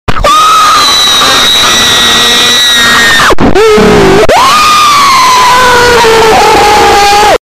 Kid Sound Effects MP3 Download Free - Quick Sounds